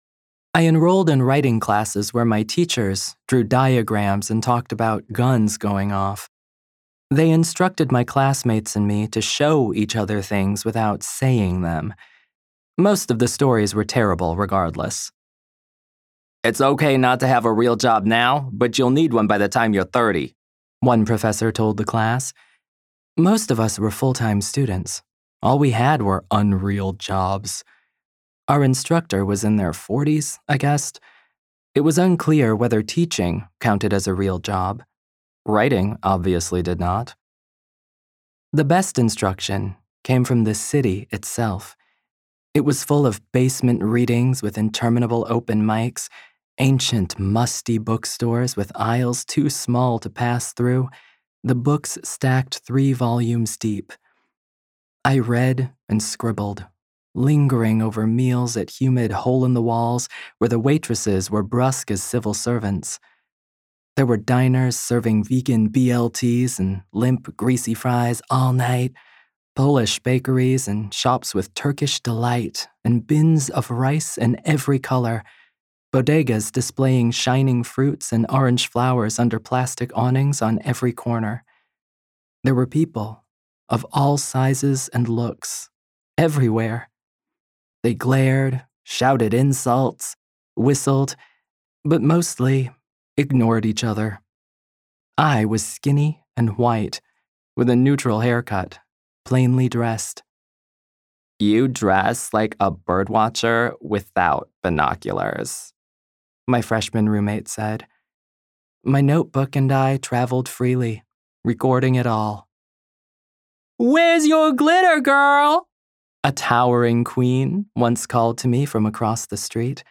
Fiction (1st person)
Non-Binary POV + characters | download